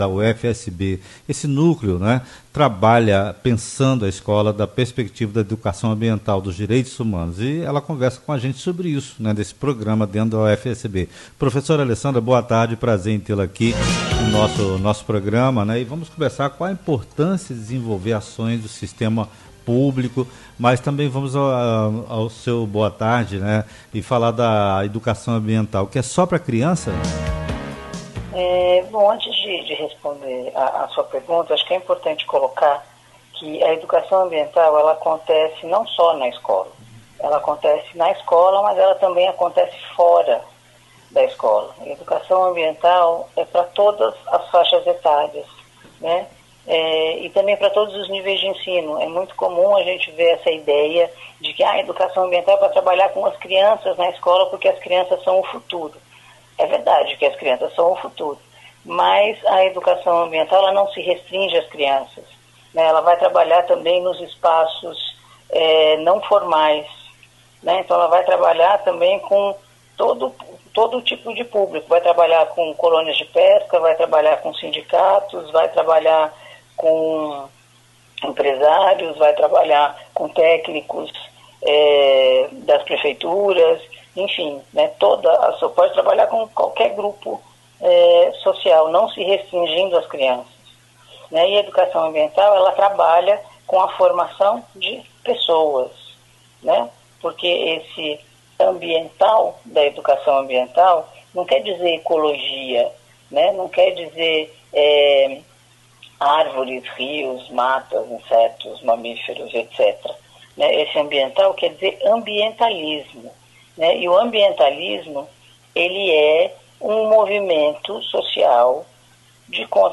SOBRE SOCIEDADES SUSTENTÁVEIS E EA: ENTREVISTA PORTO BRASIL FM